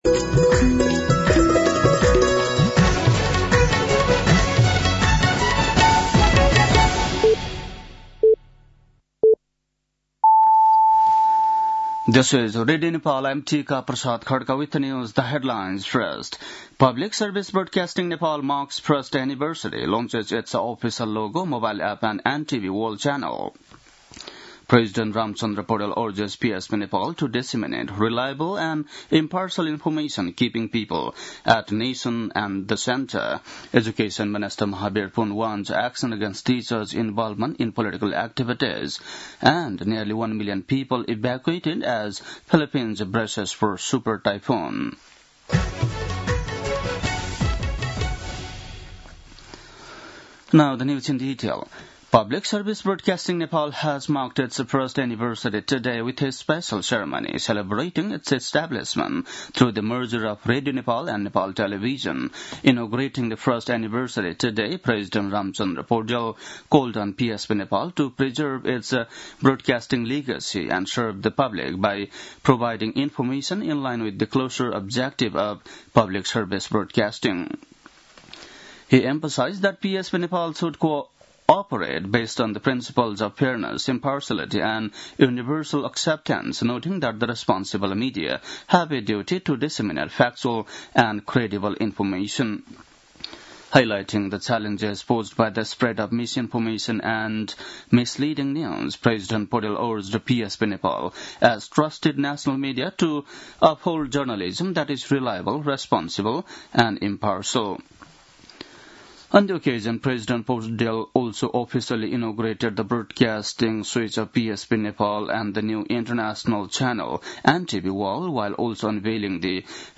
बेलुकी ८ बजेको अङ्ग्रेजी समाचार : २३ कार्तिक , २०८२
8-pm-english-news-7-23.mp3